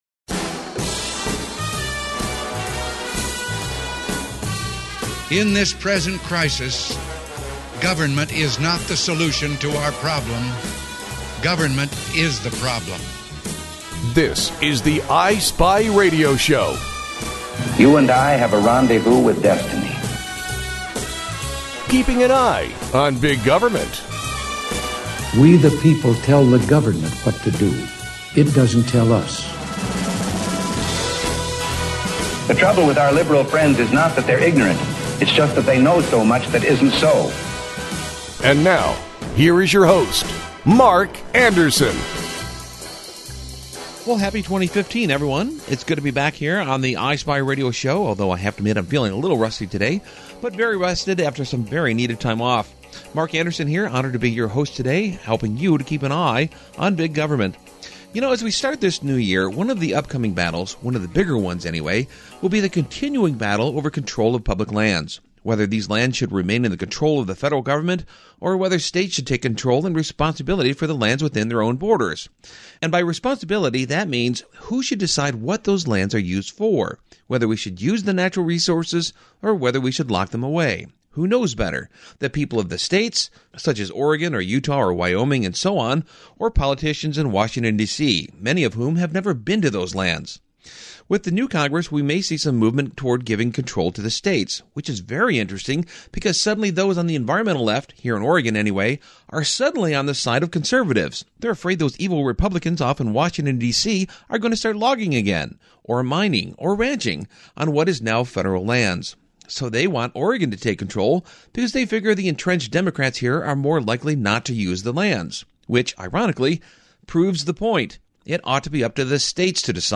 11:00AM Saturday: KUIK (Portland) | KBKR (Baker City) | KLBM (La Grande) 7:00PM Sunday: KAJO (Grants Pass)